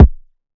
Kick (SummerTime).wav